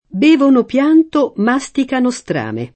masticare
mastico [ m #S tiko ], ‑chi